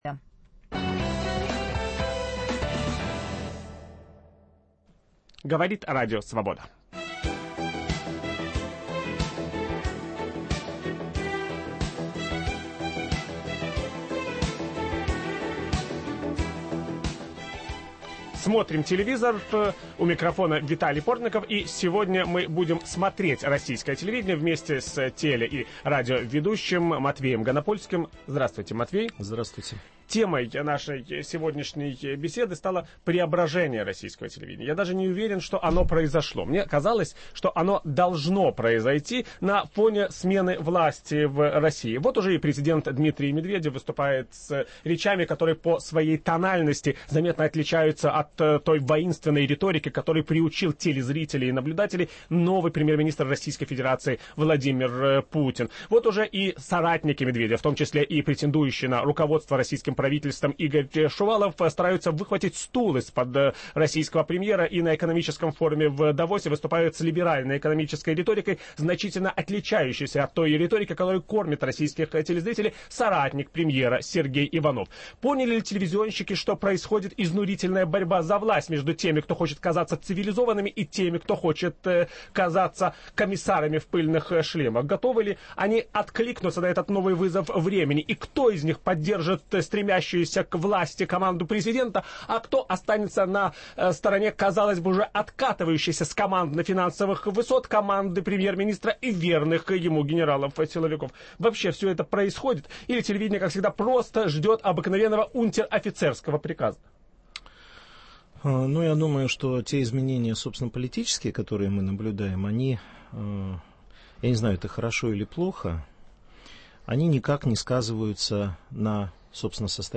Меняется ли российское телевидение в первый месяц президентства Дмитрия Медведева? Виталий Портников беседует с Матвеем Ганопольским.